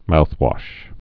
(mouthwŏsh, -wôsh)